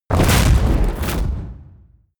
Royalty free sounds: Explosion